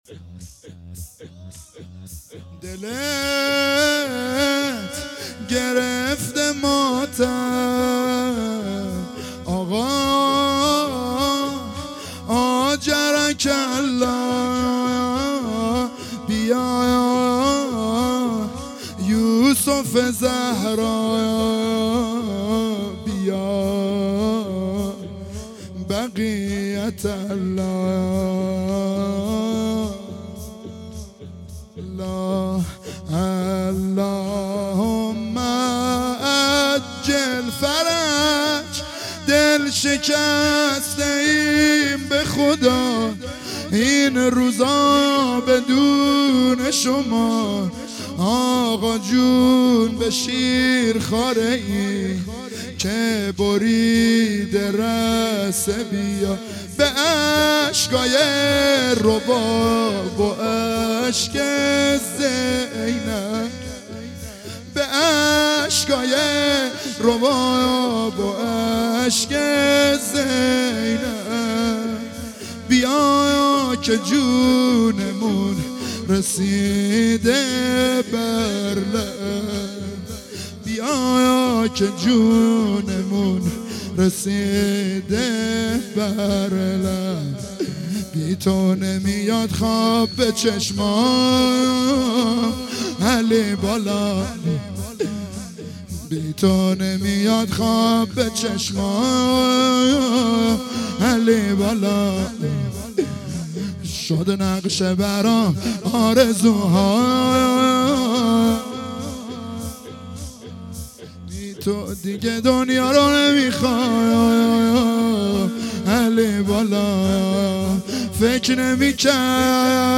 عزاداری دهه اول محرم الحرام 1442